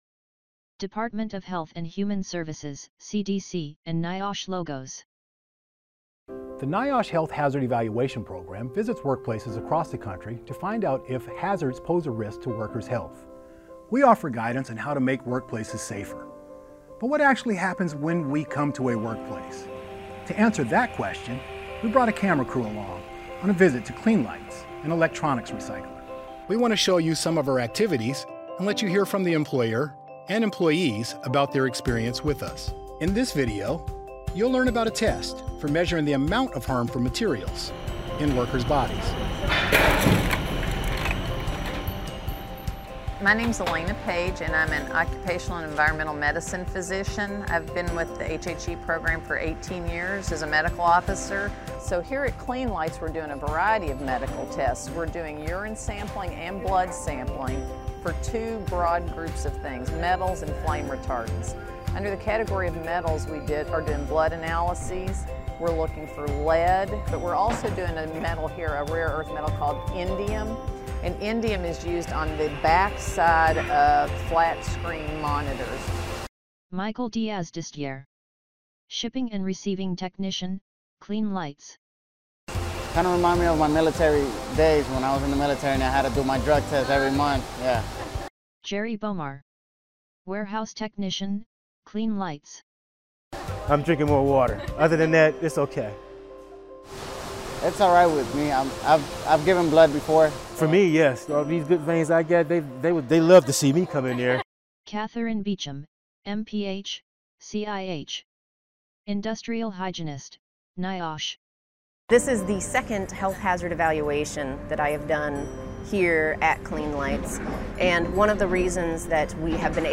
HHE-Medical-Testing-at-a-Workplace_3.8.23_AudioDescription.mp3